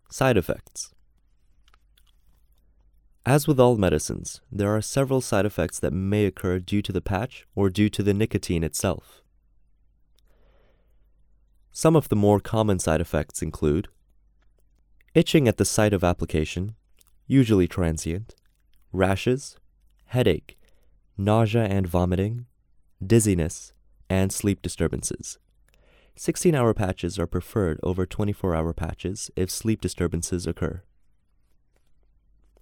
Narration audio (WAV)